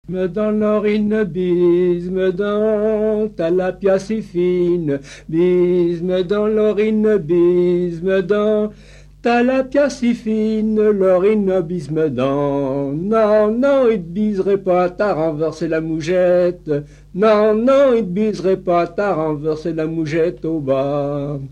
Mémoires et Patrimoines vivants - RaddO est une base de données d'archives iconographiques et sonores.
Catégorie Pièce musicale inédite